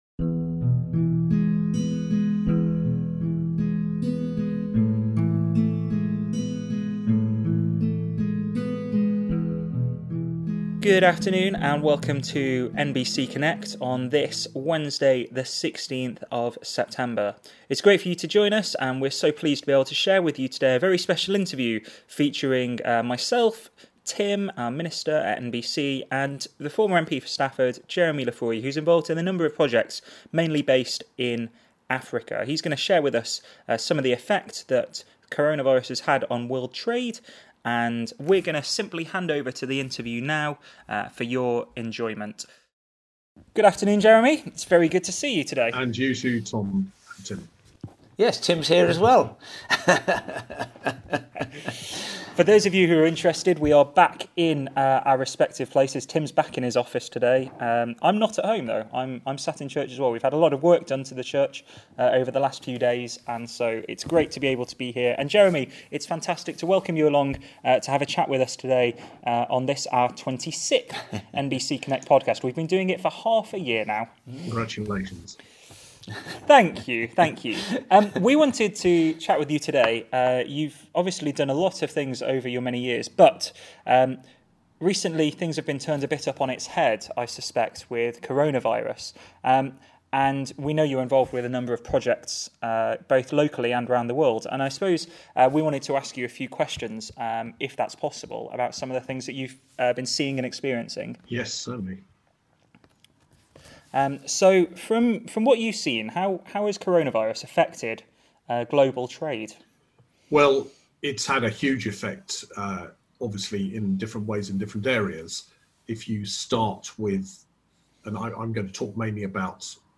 It might be a bit messy in places - we're new to this podcasting business after all - but we hope it will be a great blessing to you as we all continue onwards through these strange times.